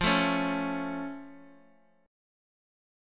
F+ Chord
Listen to F+ strummed